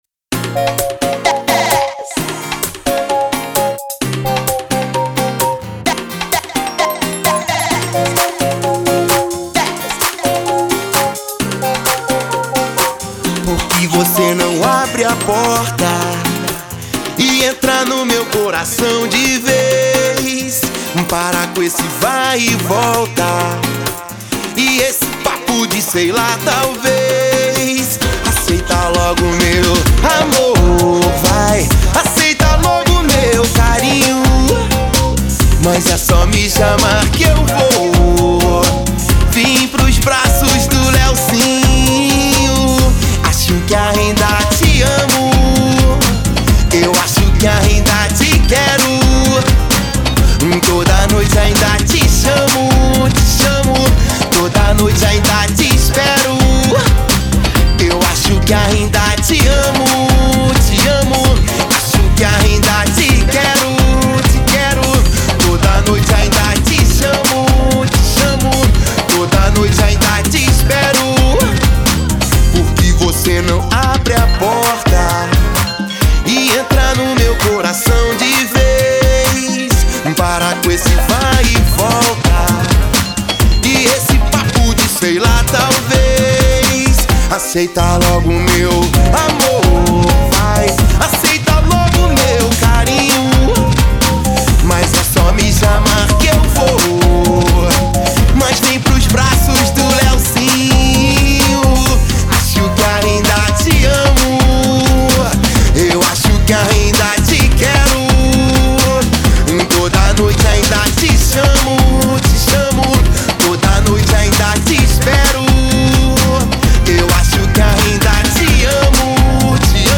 Com pegada romântica